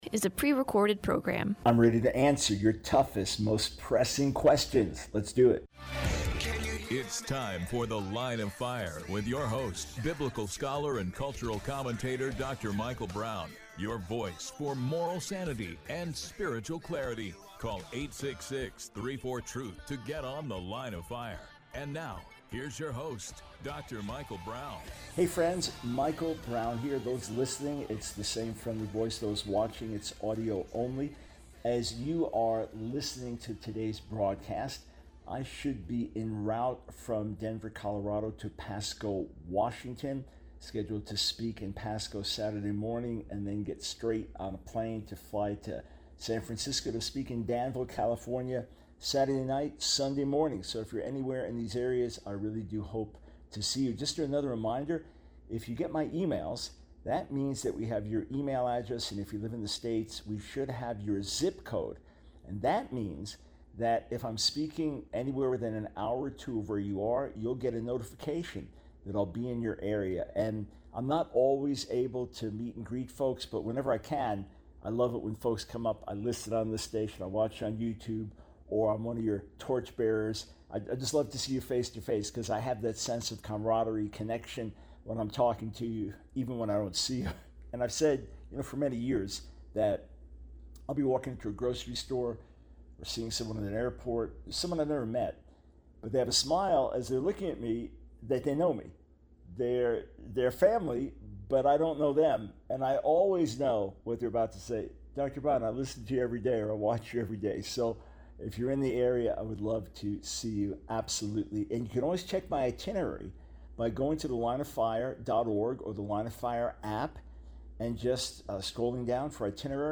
The Line of Fire Radio Broadcast for 08/16/24.